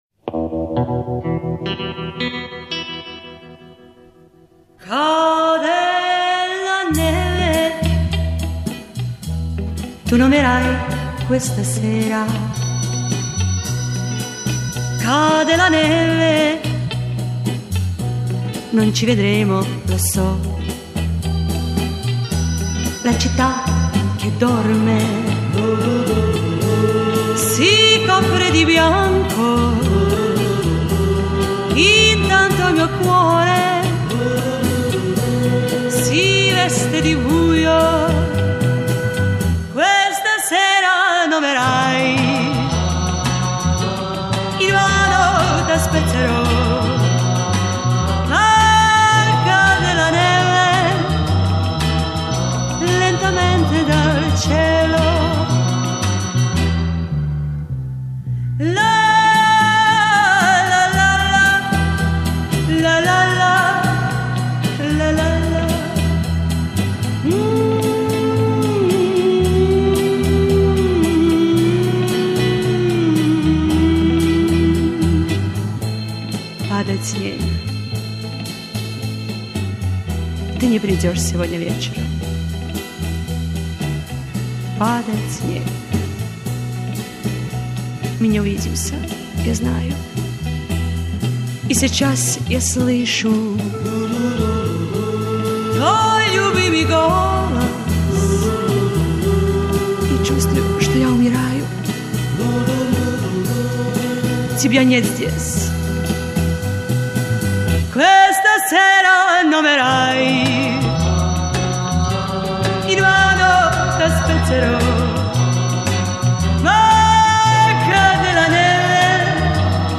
Спасибо за песню в очень приличном качестве!